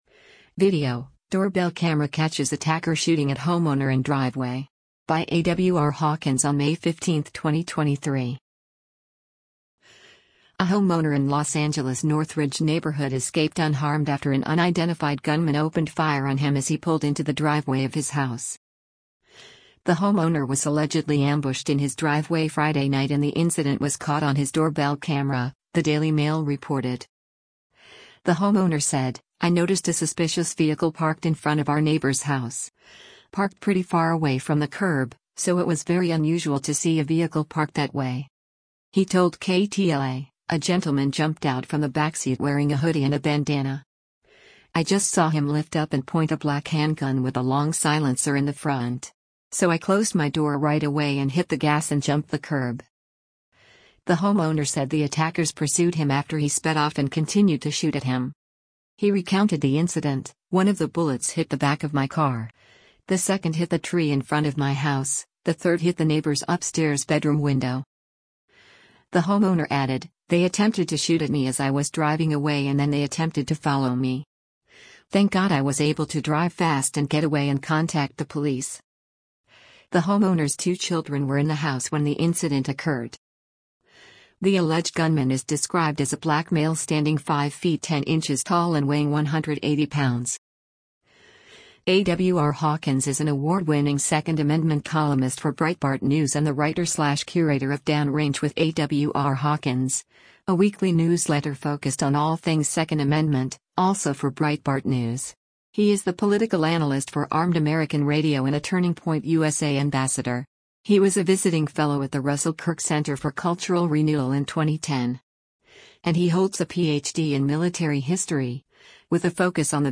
VIDEO: Doorbell Camera Catches Attacker Shooting at Homeowner in Driveway